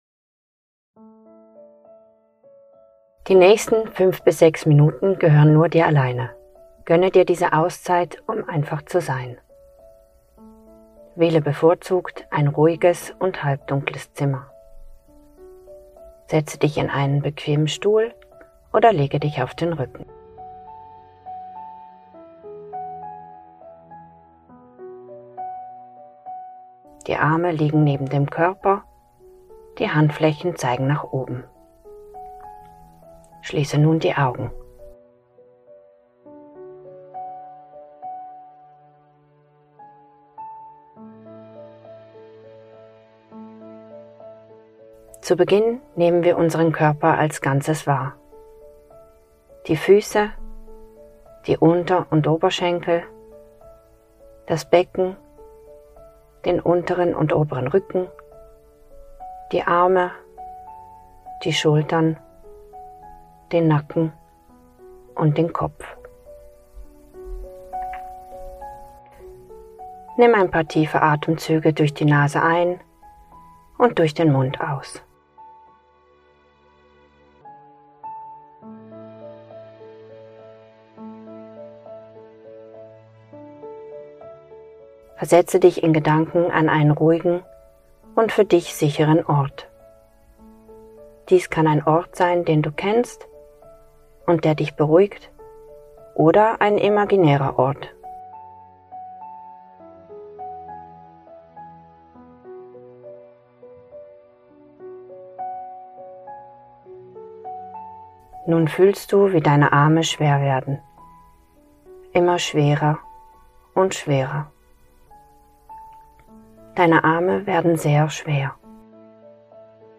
Autogenes Training mit Musik